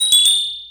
Cri d'Éoko dans Pokémon X et Y.